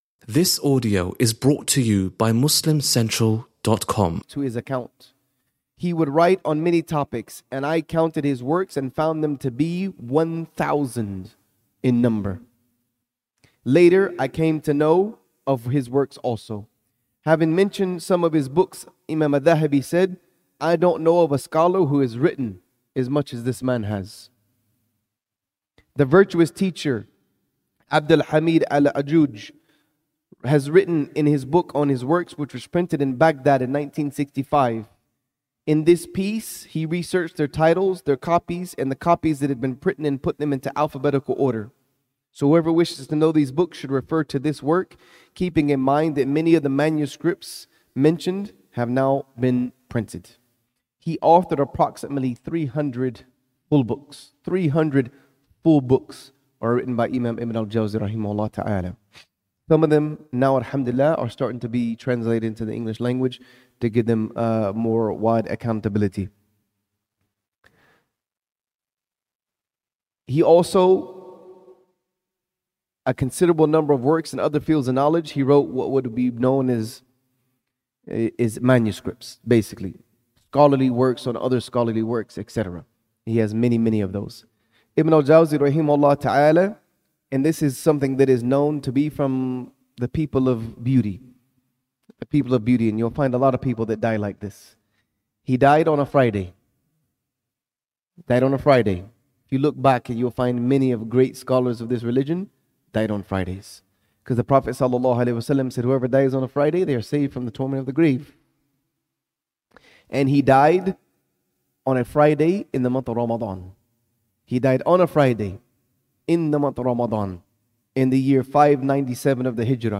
Lecture from Masjid Salahadeen